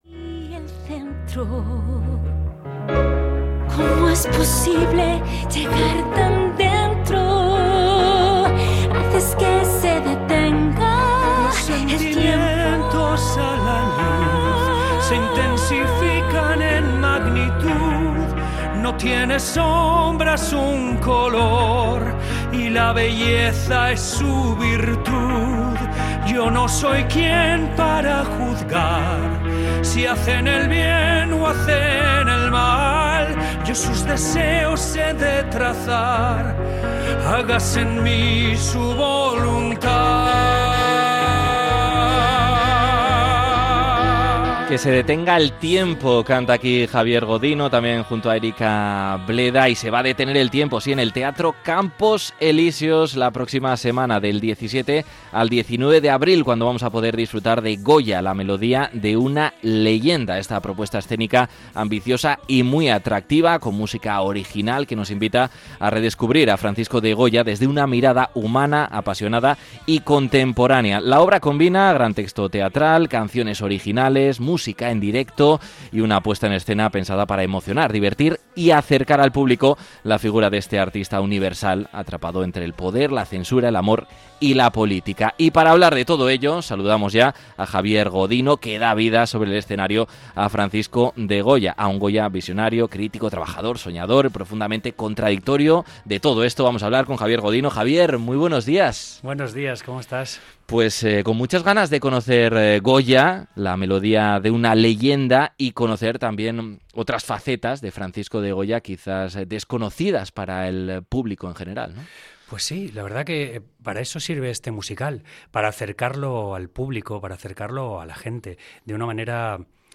Hablamos con Javier Godino, que interpreta a Francisco de Goya en el musical que llega al Teatro Campos Elíseos del 17 al 19 de abril